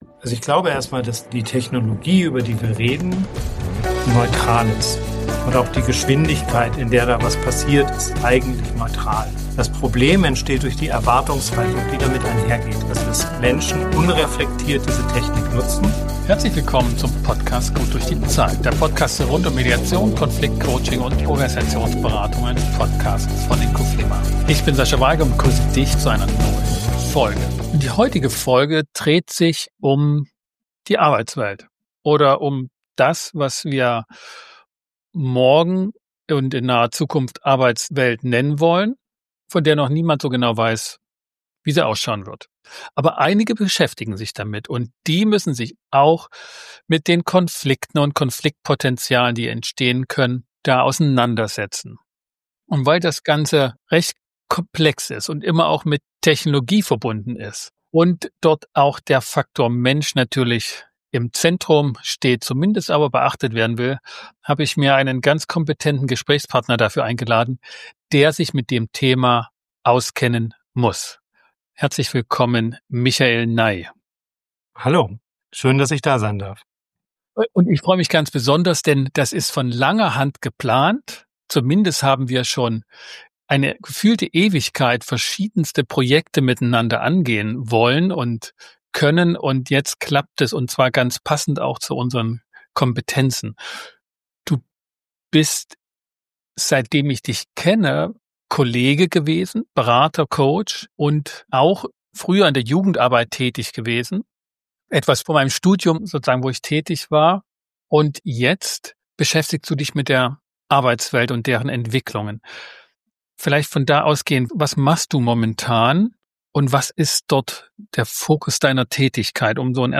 #248 GddZ - Zukunft der Arbeitswelt - Zukunft der Konfliktwelt. Im Gespräch